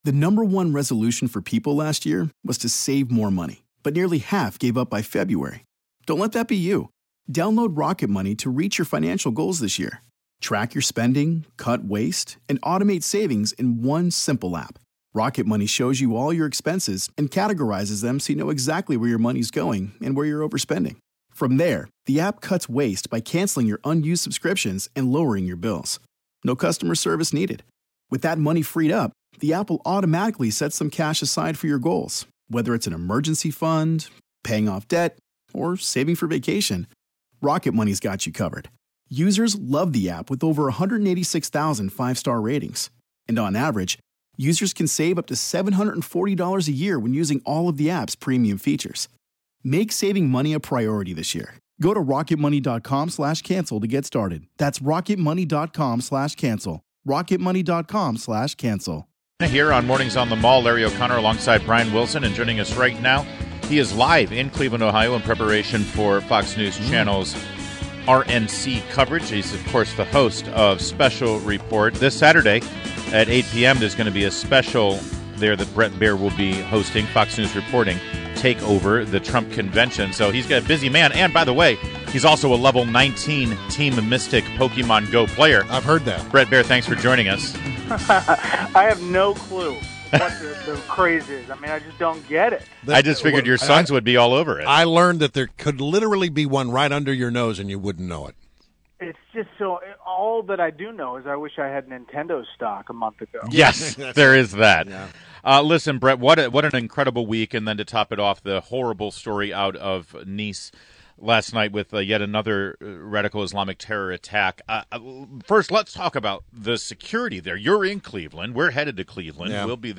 WMAL Interview - BRET BAIER - 07.15.16